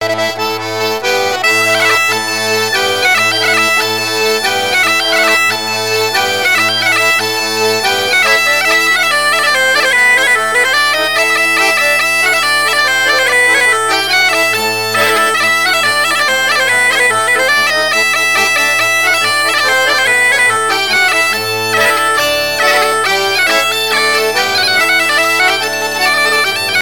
danse : ronde : grand'danse
Pièce musicale éditée